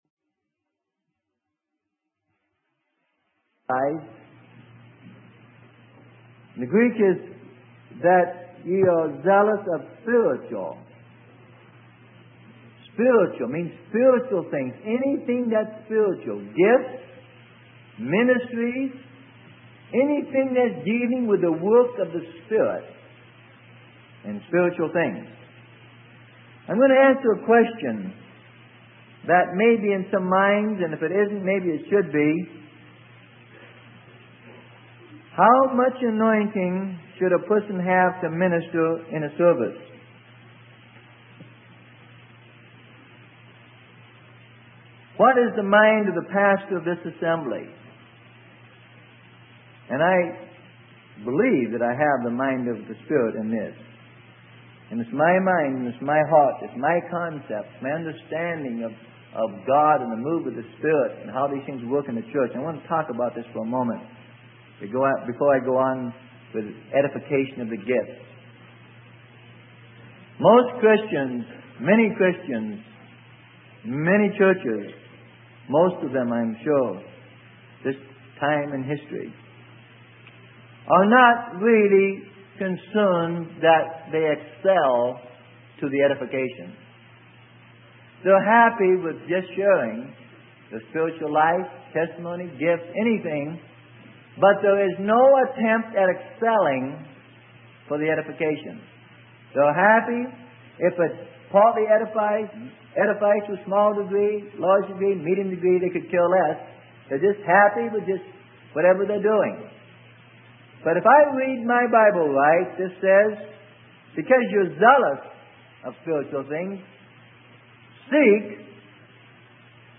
Sermon: Seek That You May Excel - Part 3 - Freely Given Online Library